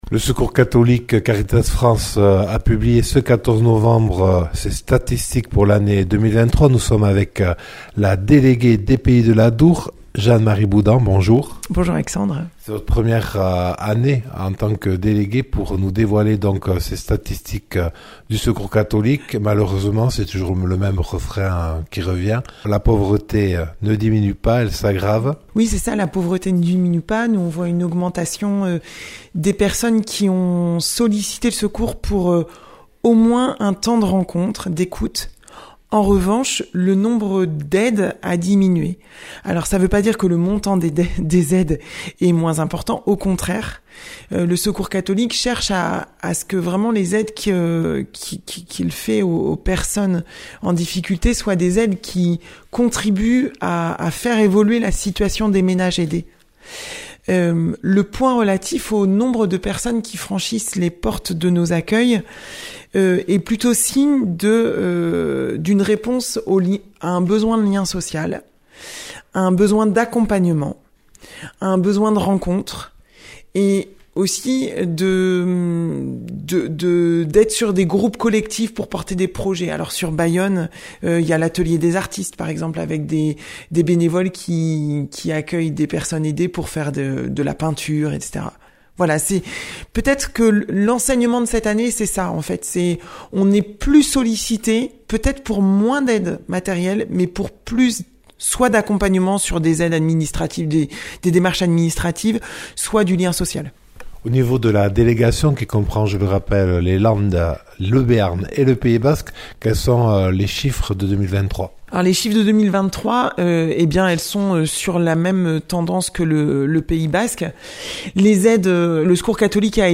Interviews et reportages